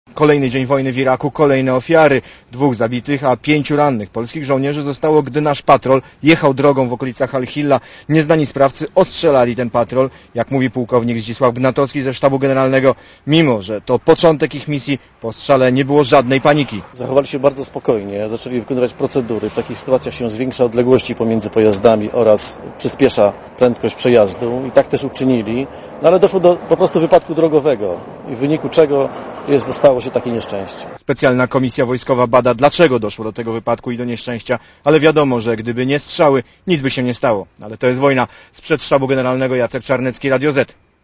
reportera Radia ZET*